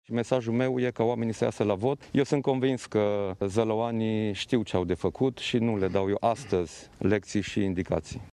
Și fostul premier Dacian Cioloș ne îndeamnă să mergem la vot. Liderul Plus a votat în Zalău.